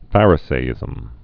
(fărĭ-sā-ĭzəm) also phar·i·see·ism (-sē-ĭzəm)